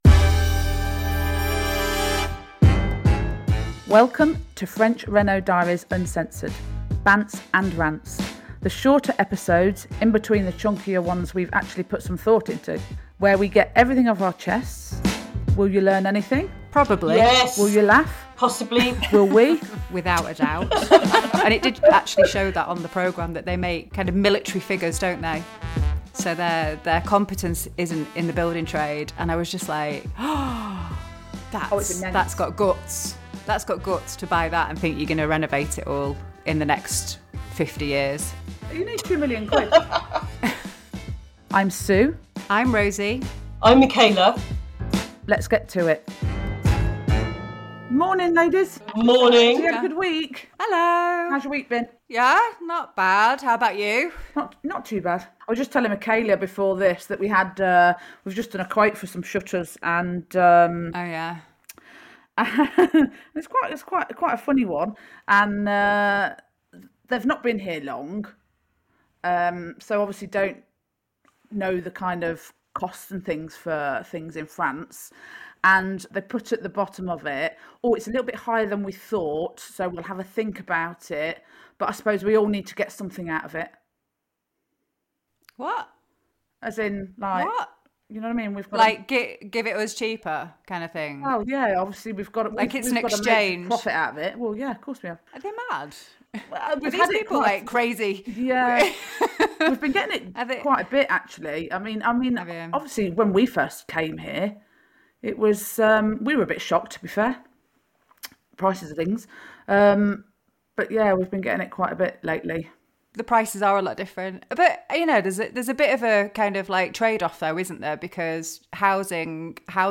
Rants & Bants episodes are informal, off-the-cuff chats - think of them like secretly recorded calls. Less polish, more spontaneity - but always insightful!